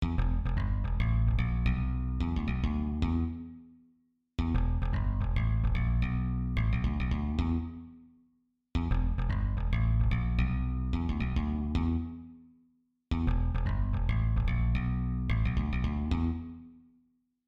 低音循环1 110 Bpm Am Am F G
描述：贝斯循环1的3个吉他和贝斯循环的集合。
Tag: 110 bpm Pop Loops Bass Loops 1.47 MB wav Key : A